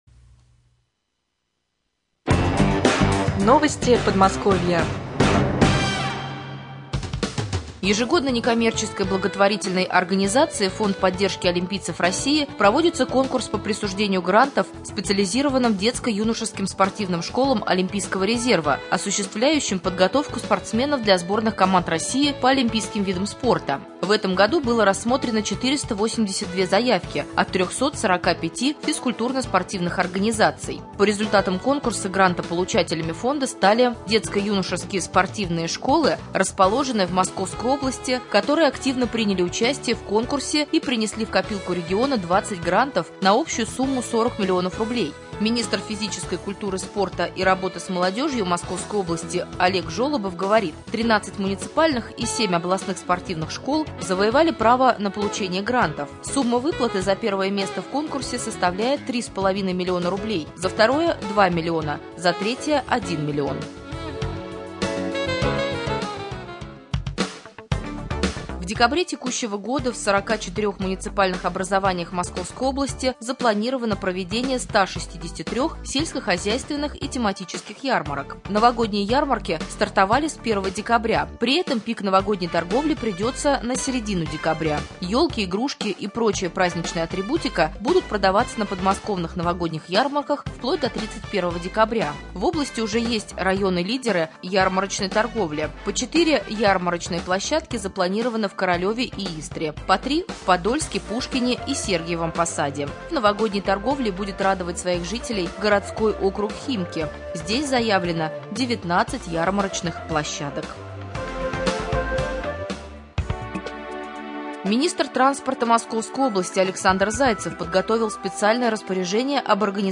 18.12.2013г. в эфире раменского радио
Новости Подмосковья.